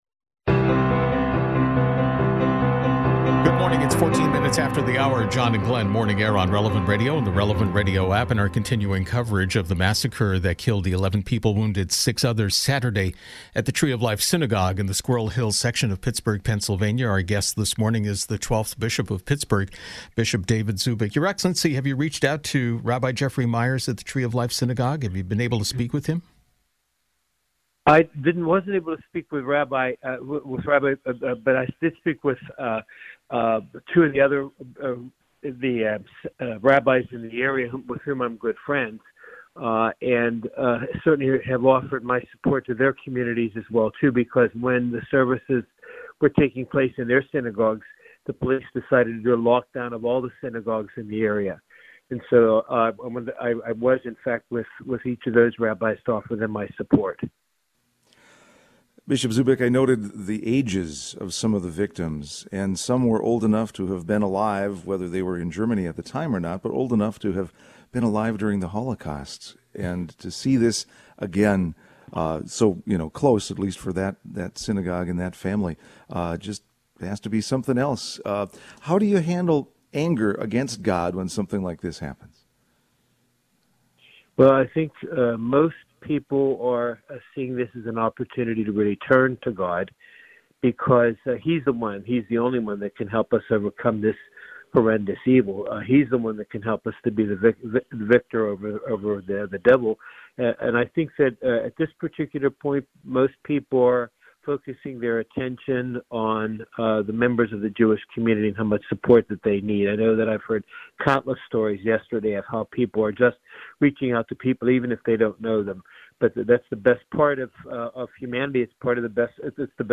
Bishop Zubik of the Diocese of Pittsburgh spoke with Morning Air® early Monday morning about the tragedy that took place in his city over the weekend.